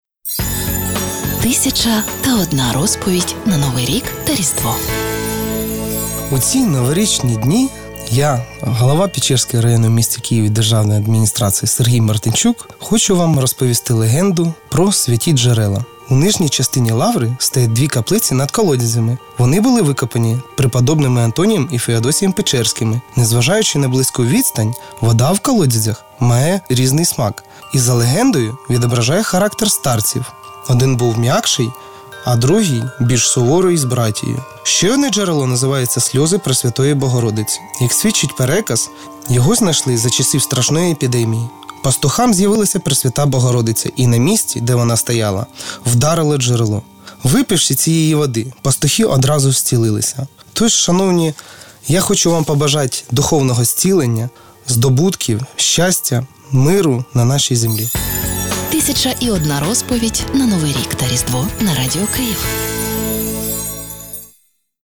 Голова Печерської райдержадміністрації Сергій Мартинчук записав різдвяне привітання, яке буде транслюватиметься на радіо «Київ 98FM» під час свят. У своєму вітальному слові очільник району розповів про славнозвісні Святі джерела Києво–Печерської лаври.